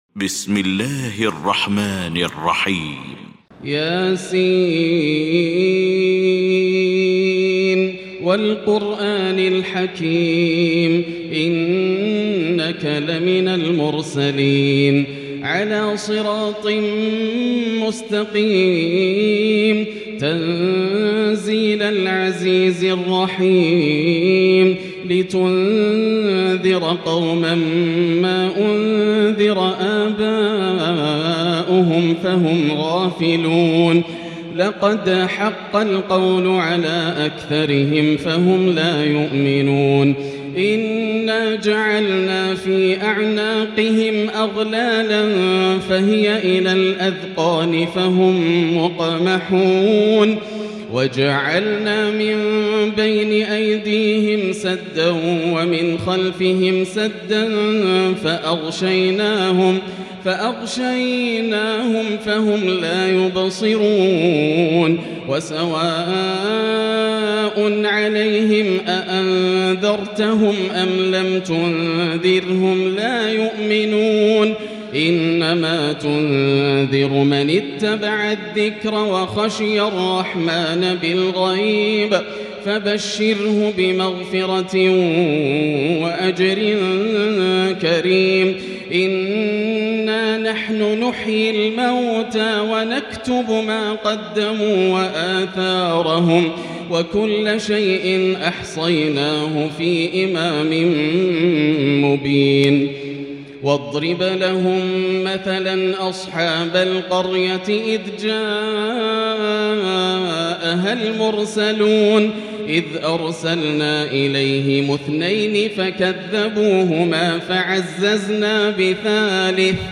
المكان: المسجد الحرام الشيخ: فضيلة الشيخ ياسر الدوسري فضيلة الشيخ ياسر الدوسري يس